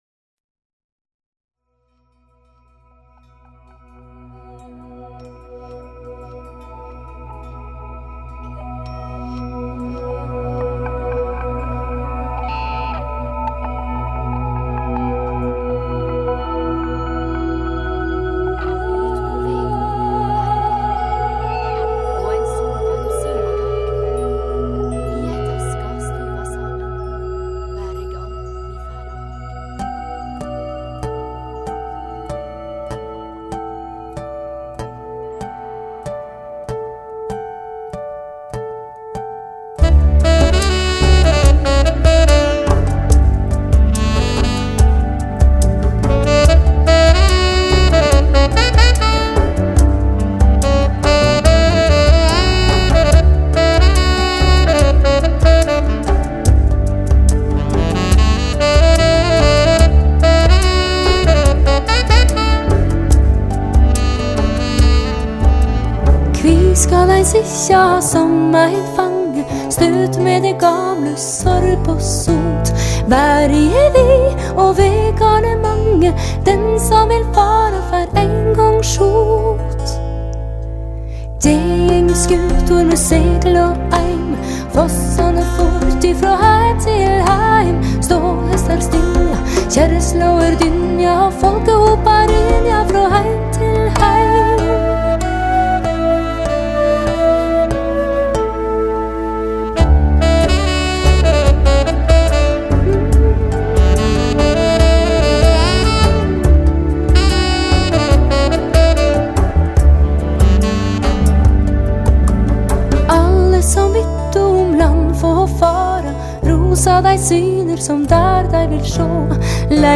融合古典、摇滚、北欧乡村风、印度民族风、苏格兰曲风、柔情女声、吟游诗歌..等多元乐风
录音定位清楚、音质细腻清晰、音像深度及宽度精采可期
透过人声、小提琴、萨克斯风、双簧管、贝斯、吉他、钢琴、打击乐器、印度笛、竖笛、扬琴、